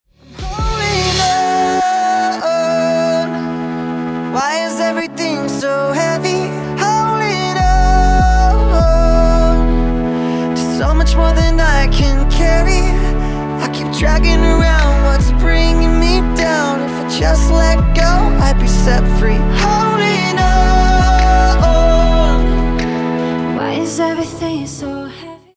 • Качество: 320, Stereo
мужской вокал
dance
Melodic
romantic
vocal